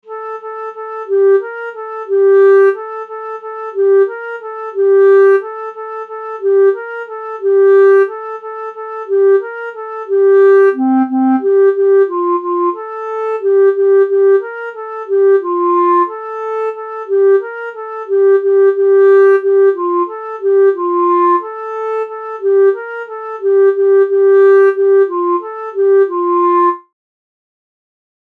Cramignon